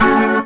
B3 FMIN 2.wav